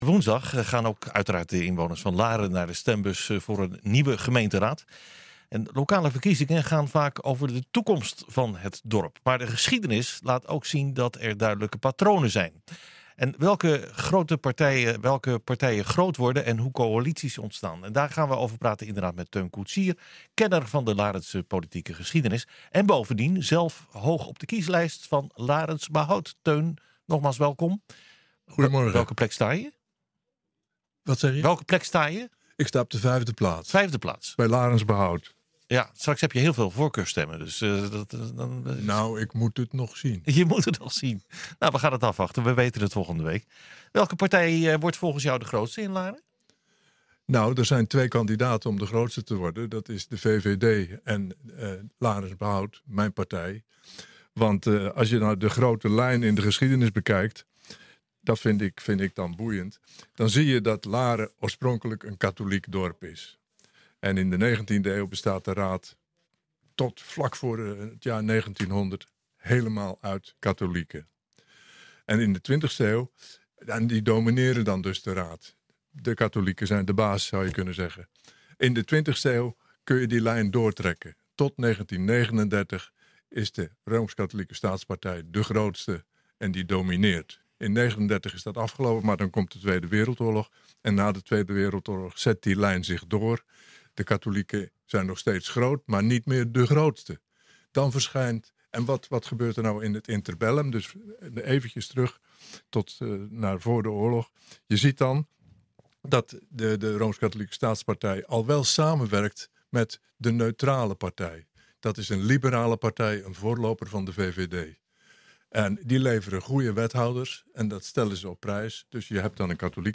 Lokale verkiezingen gaan vaak over de toekomst van het dorp, maar de geschiedenis laat ook zien dat er duidelijke patronen zijn: welke partijen groot worden en hoe coalities ontstaan. Daarover praten we vandaag met onze gast